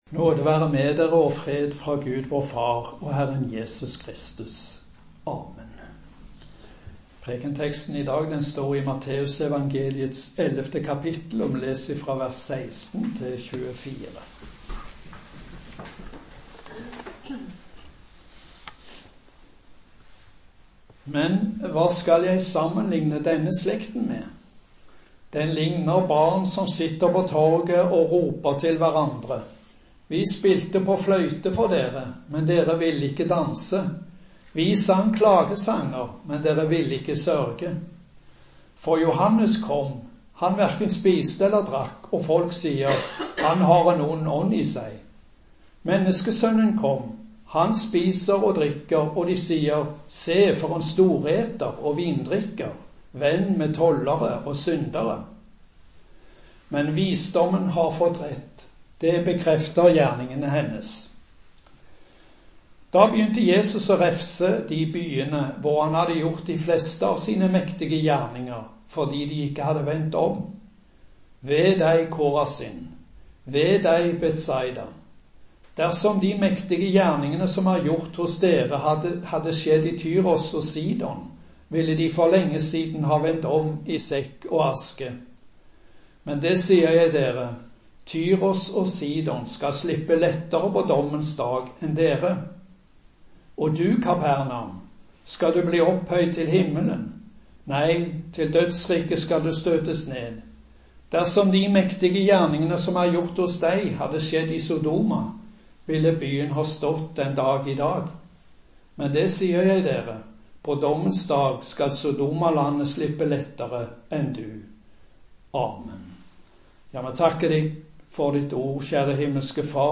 Preken på 10. søndag etter treenighetsdag